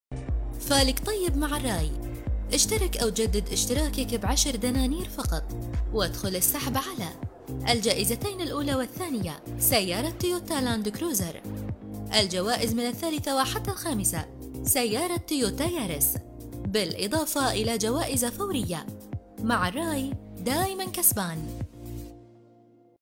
اعلان تلفزيوني تجاري باللهجة الكويتية للاشتراك في جريدة الراي – عرض على تلفزيون الراي ( فالك طيب مع الراي انت دايماً كسبان )
اعلان تلفزيوني تجاري – تلفزيون الراي